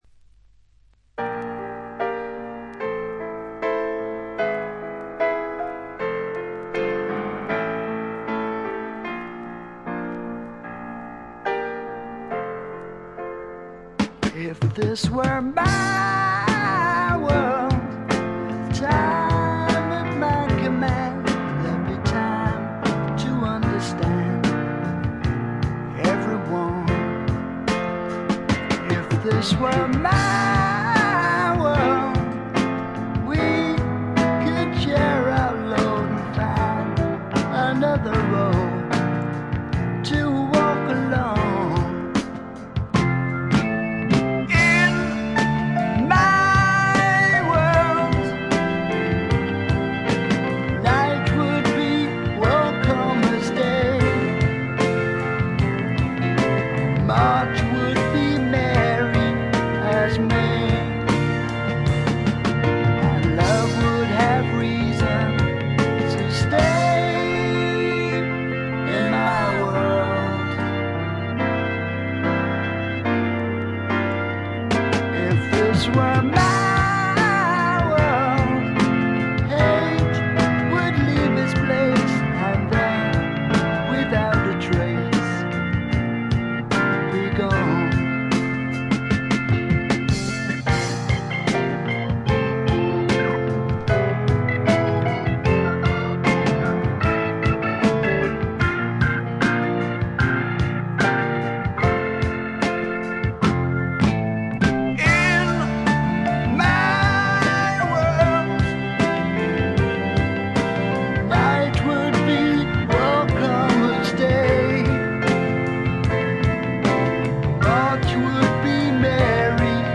わずかなノイズ感のみ。
典型的かつ最良の霧の英国、哀愁の英国スワンプ路線の音作りで、端的に言って「アンドウェラしまくり」です。
搾り出すような激渋のヴォーカルがスワンプ・サウンドにばっちりはまってたまりません。
試聴曲は現品からの取り込み音源です。